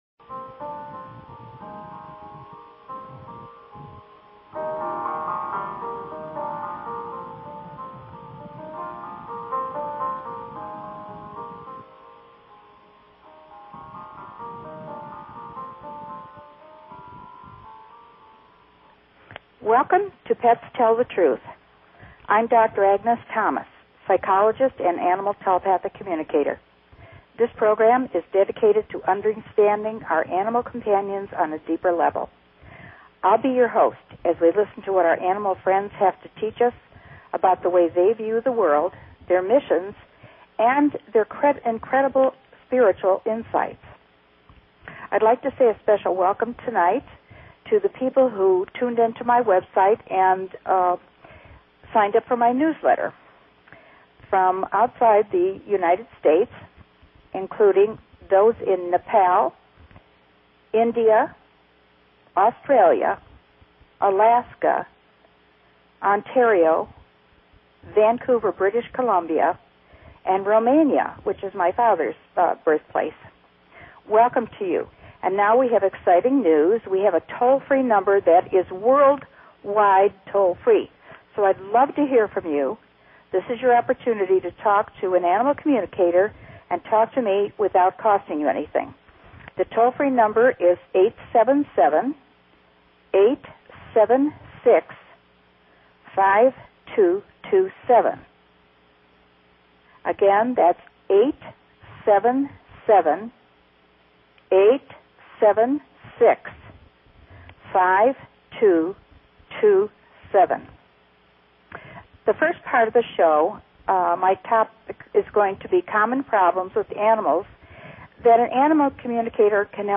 Talk Show Episode, Audio Podcast, Pets_Tell_The_Truth and Courtesy of BBS Radio on , show guests , about , categorized as
Topic: Solving problems with animals - CALL-INS Always